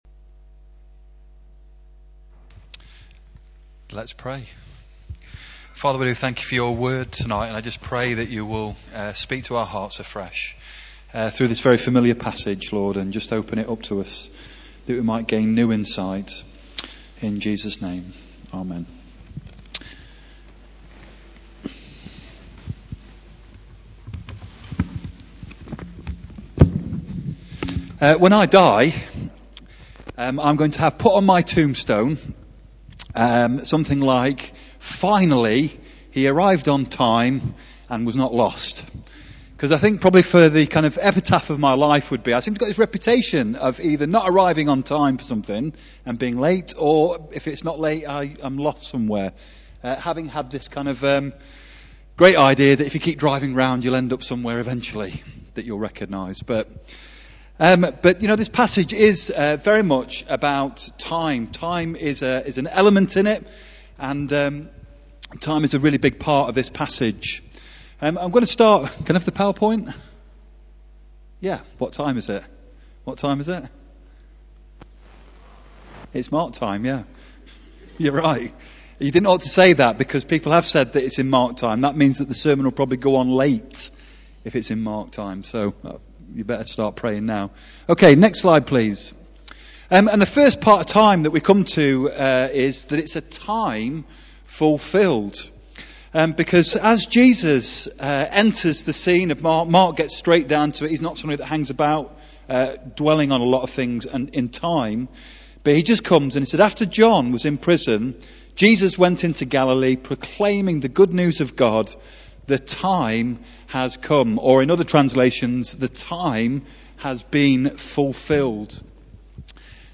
Talk
18:00 Evening Worship, St John's service